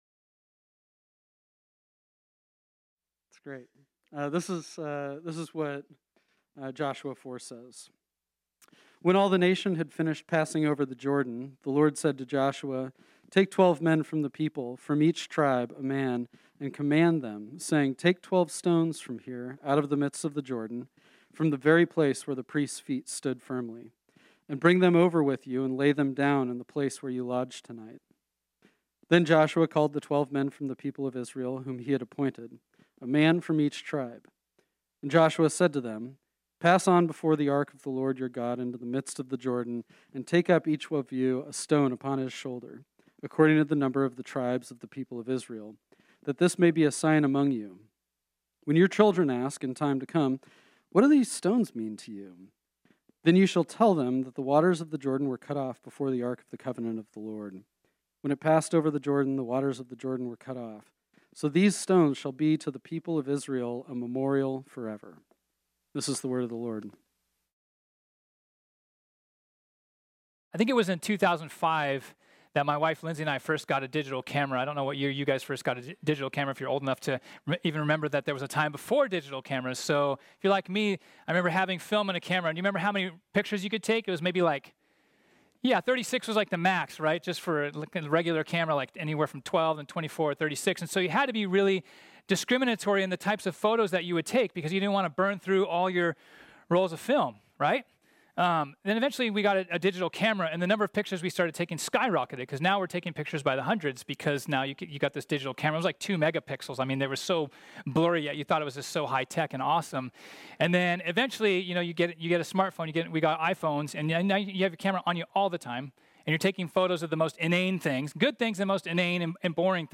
This sermon was originally preached on Sunday, October 4, 2020.